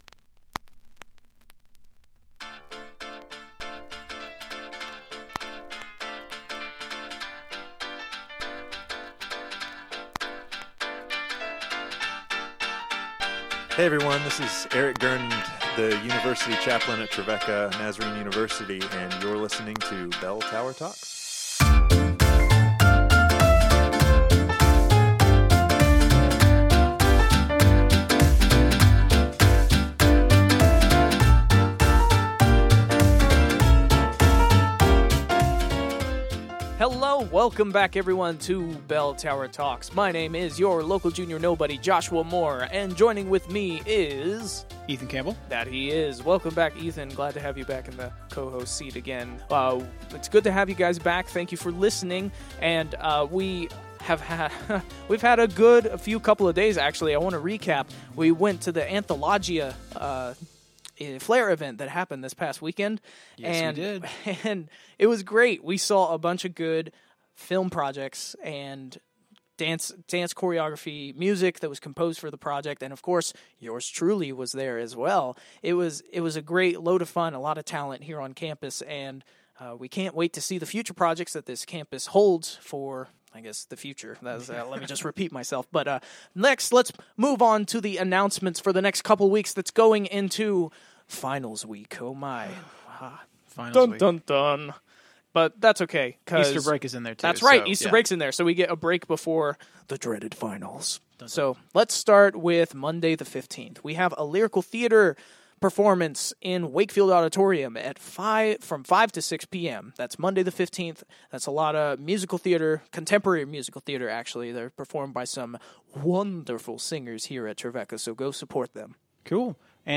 Bell Tower Talks is the Campus Life Podcast for Trevecca Nazarene University. Providing campus announcements and interviews with the interesting people at Trevecca.